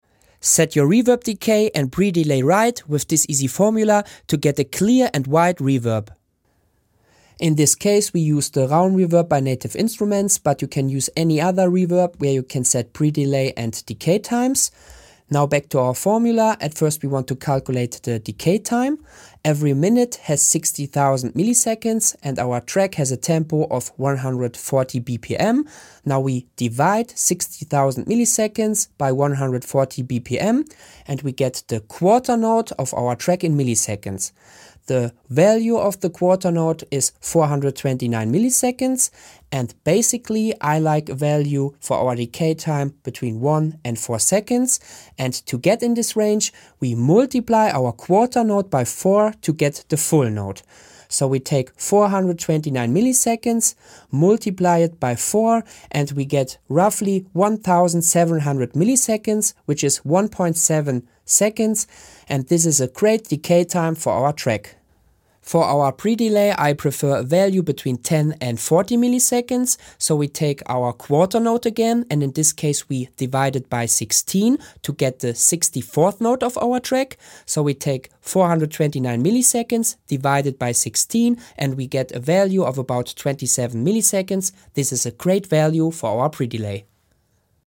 The reverb pre delay and decay sound effects free download
Reverb: Raum